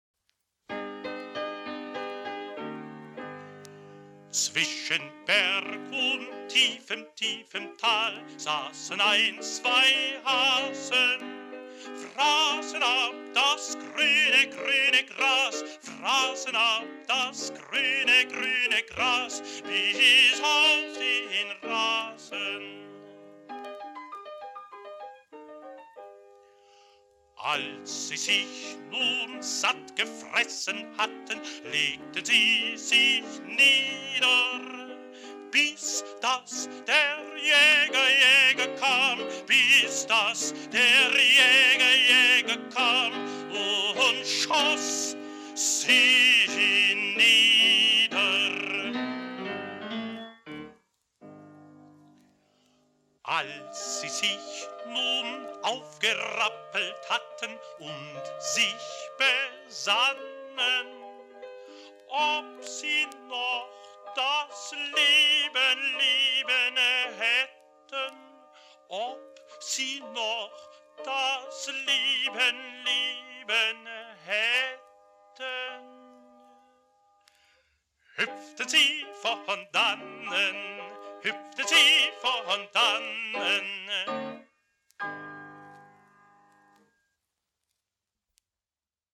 Kinder- und Volkslied